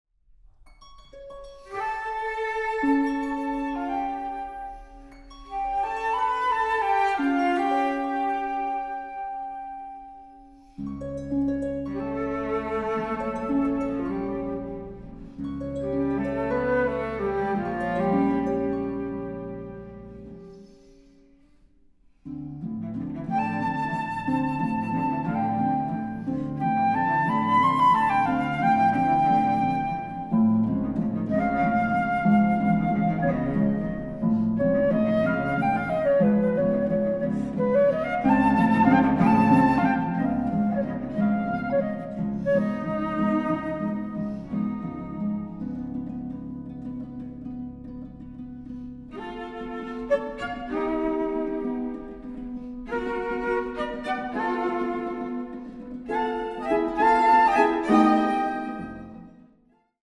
arranged for flute, cello, and harp